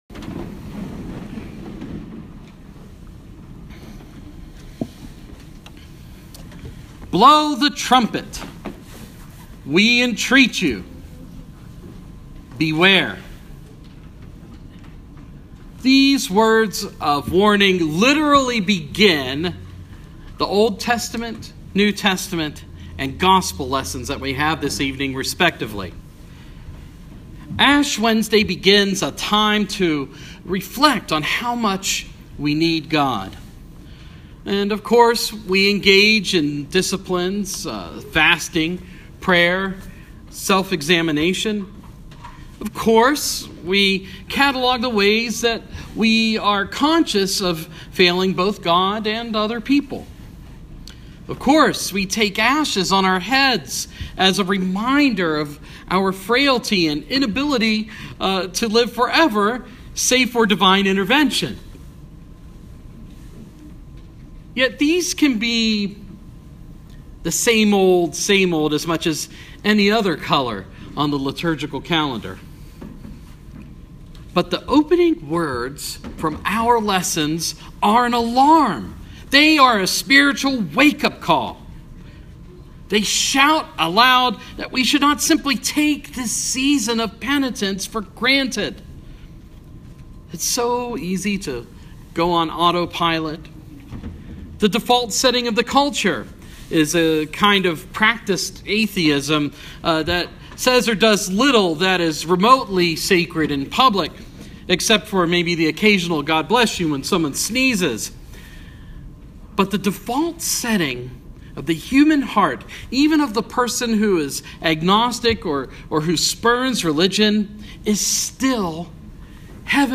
Ash Wednesday Homily